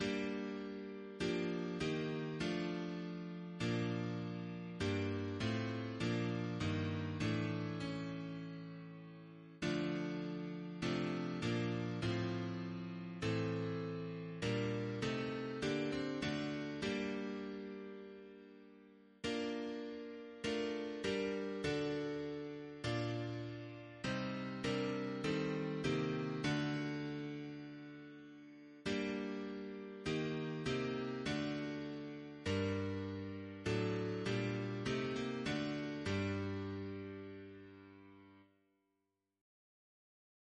Quadruple chant in G minor Composer: Chris Biemesderfer (b.1958) Note: after Cruger, for Psalm 51